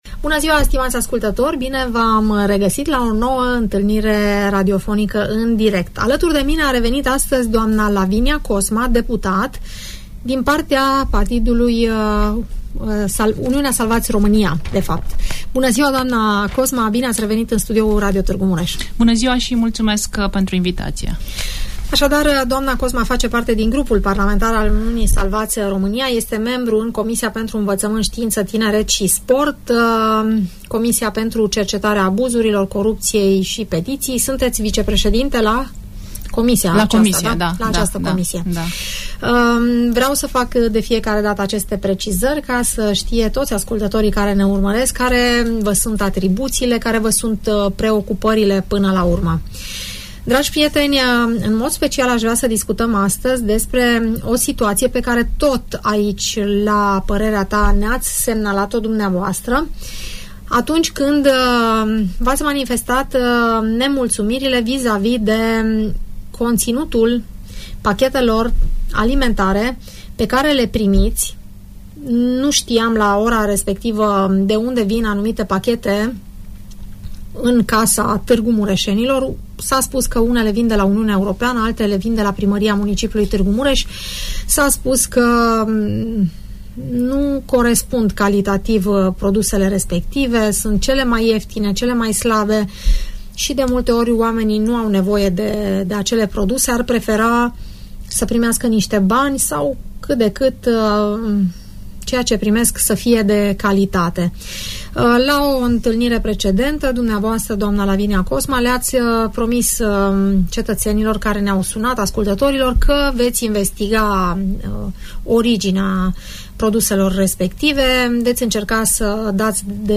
Lavinia Cosma, deputat de Mureș, în direct la Radio Tg.Mureş